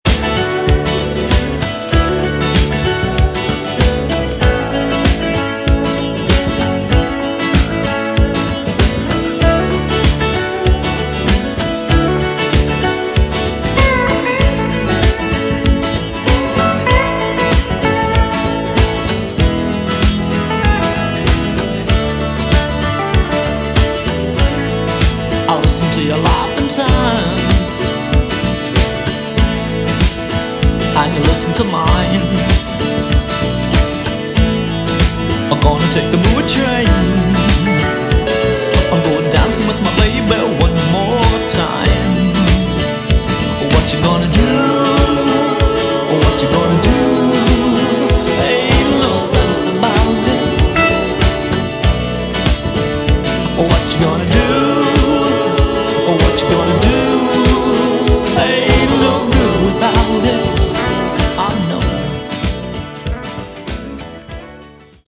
Category: Westcoast AOR